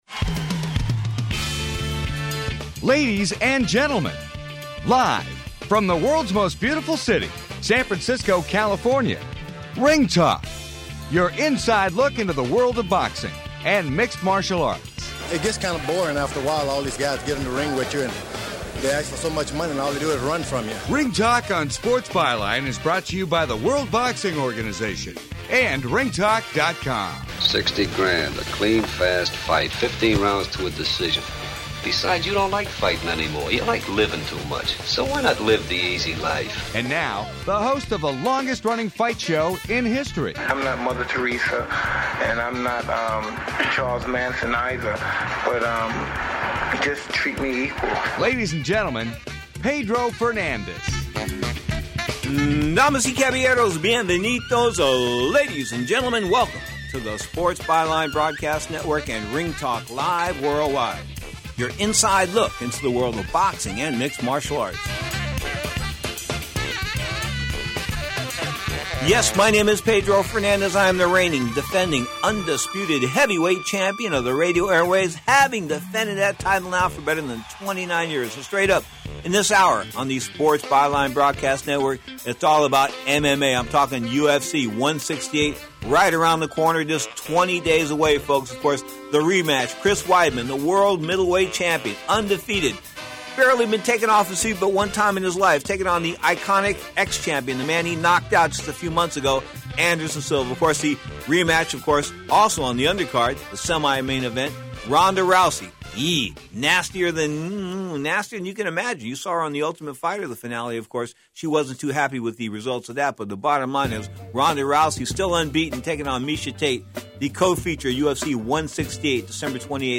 Podcast: Play in new window | Download NO BETTER MMA GUY THAN DAVE MELTZER Dave “MMA Genius” Meltzer San Francisco, CA – Ring Talk Worldwide , the longest running fight show in history, 29+ years, reviewed UFC FIGHT NIGHT from Australia Friday night. The prolific MMA writer in history Dave Meltzer talked the UFC and more.